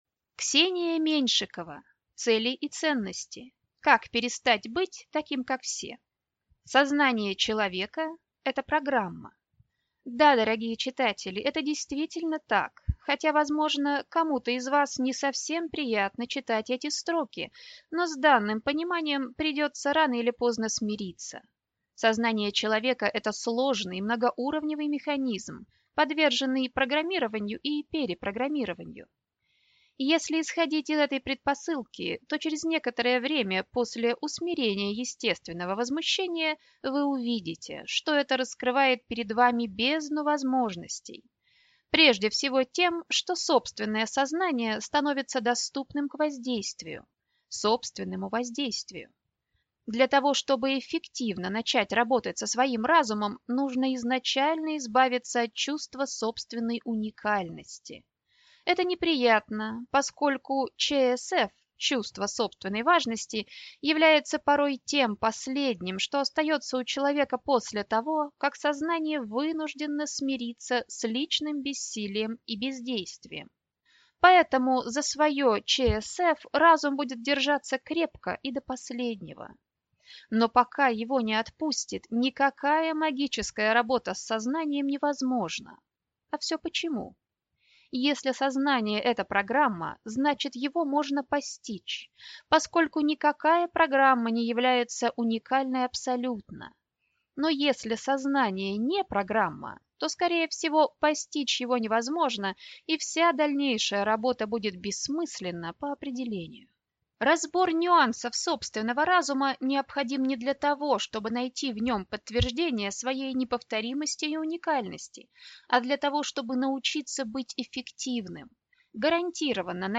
Аудиокнига Цели и ценности. Как перестать быть таким, как все | Библиотека аудиокниг